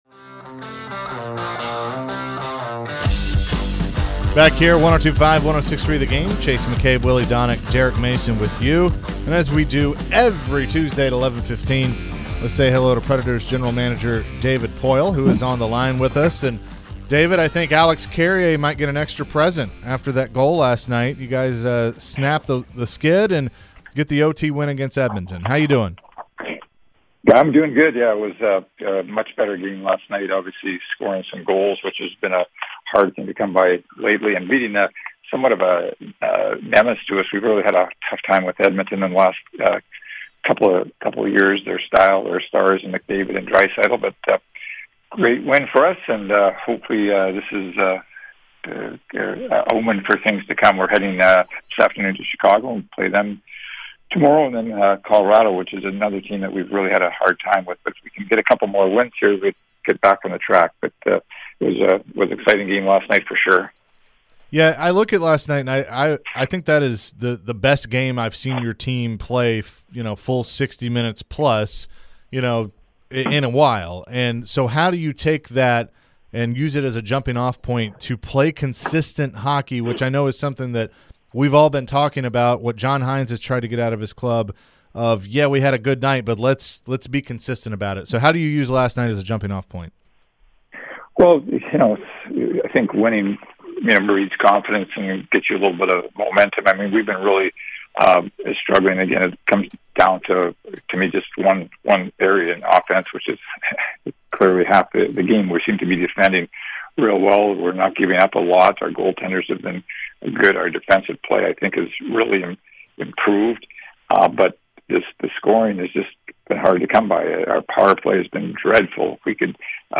Preds GM David Poile Interview (12-20-22)